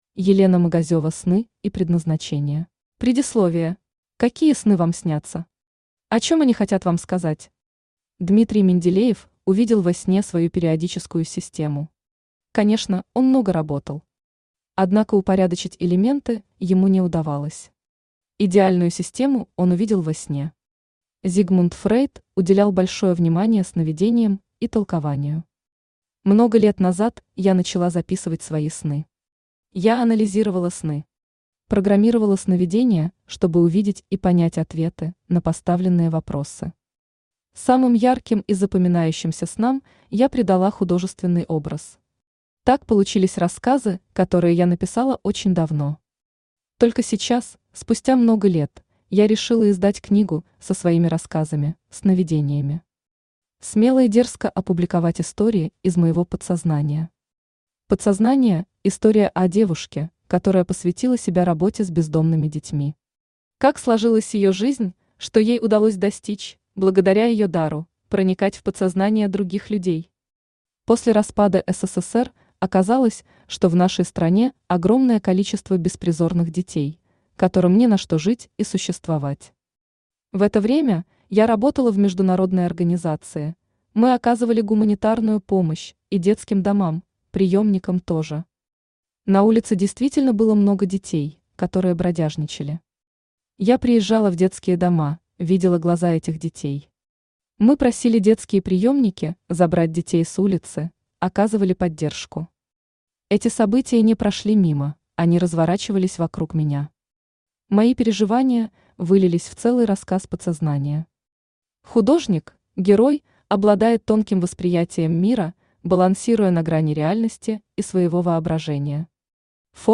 Aудиокнига Сны и Предназначение Автор Елена Магазева Читает аудиокнигу Авточтец ЛитРес.